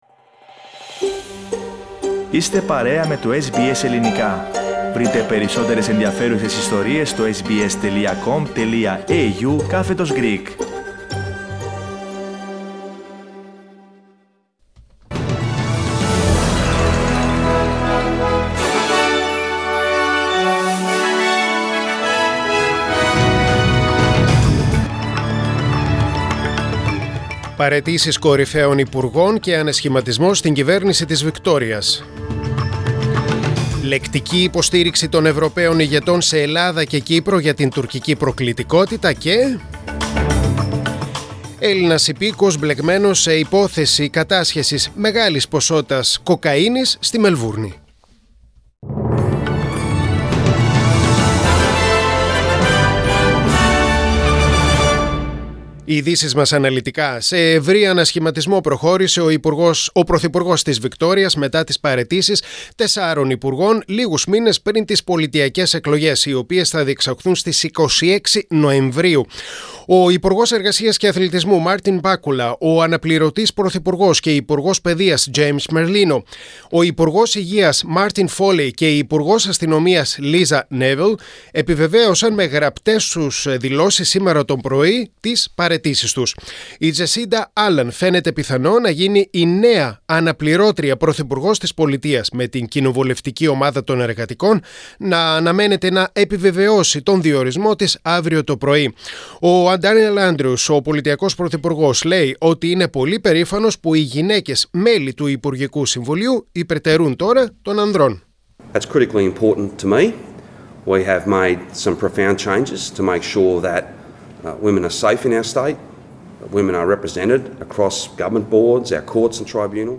Δελτίο Ειδήσεων: Παρασκευή 24.6.2022